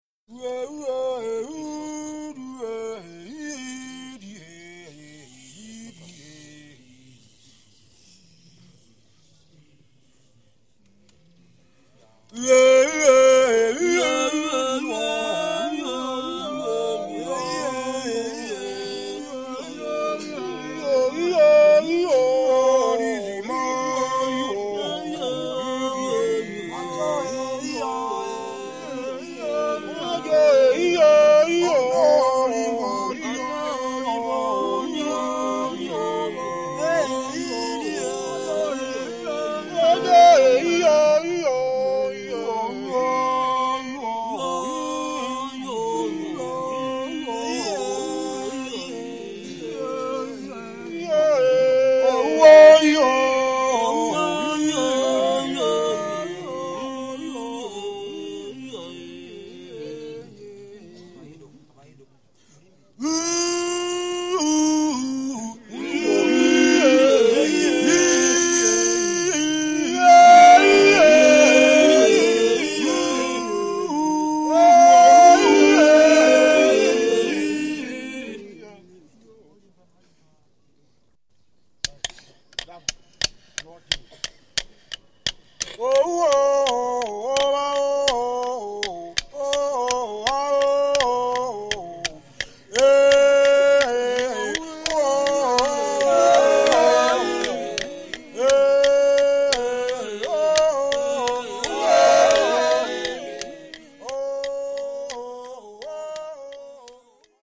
Music
They often sing, dance and play instruments such as the drum.
Women often burst into song, when men come back from a successful hunt.
A recording of ‘The Honey Harvest’ by Congolese Mbuti Pygmies.